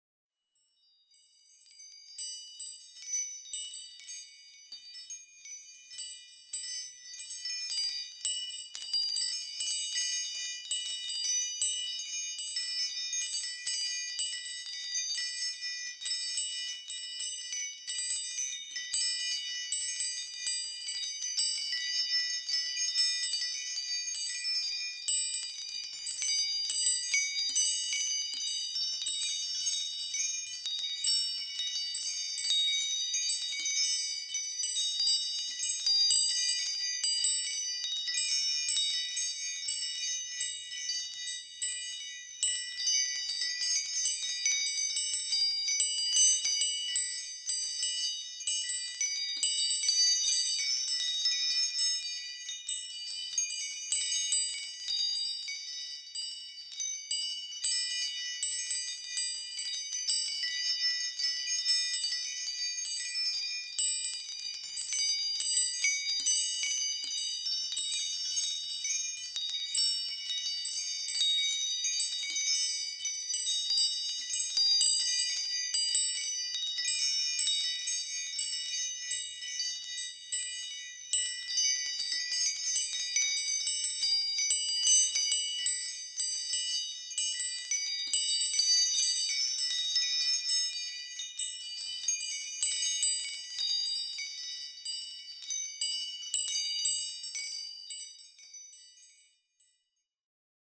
Звук раскачивающейся люстры Бытовые шумы
Звук раскачивающейся люстры
Звук раскачивающейся большой роскошной люстры с мелкими дрожащими хрустальными подвесками. Легкий перезвон висюлек, когда задели люстру, свисающую с потолка.
zadeli-hrustalnuju-ljustru.mp3